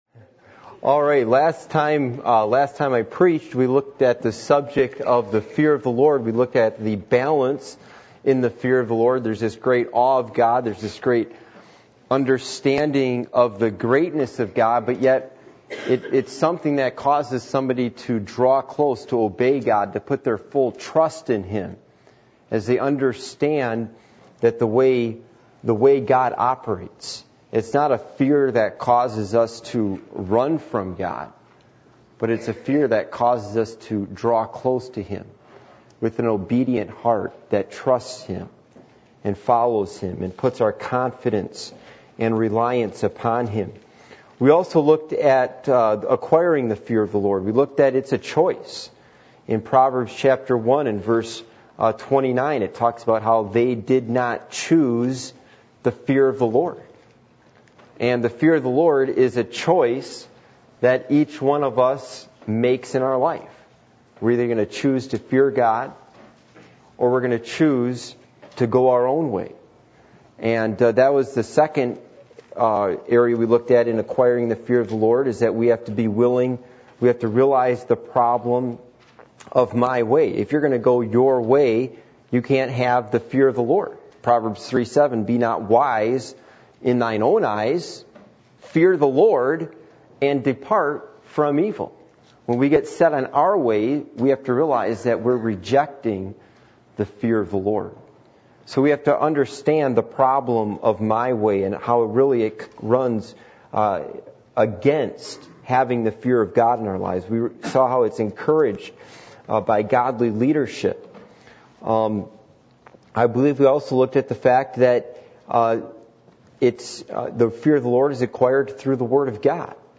Service Type: Midweek Meeting